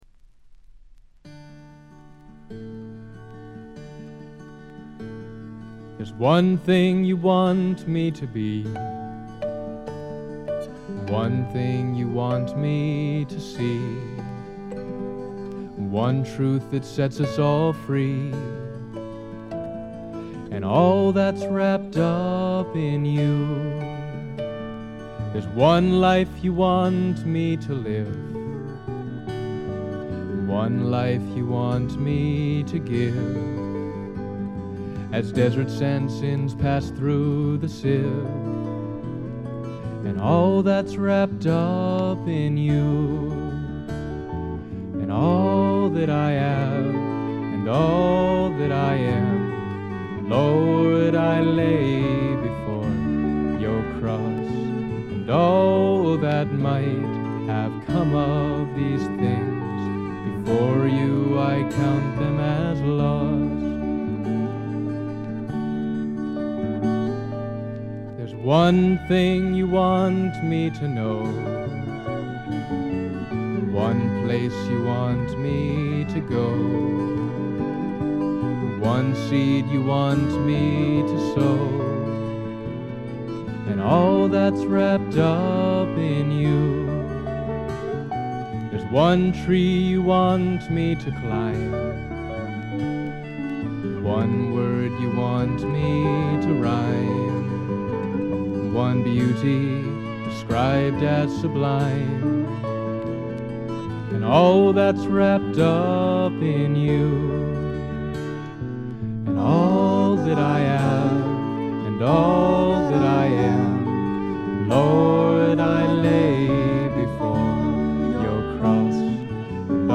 全編を通じて見事にサイレントで聖なる世界が展開します。
ずばりドリーミーフォークの名作と言って良いでしょう。
試聴曲は現品からの取り込み音源です。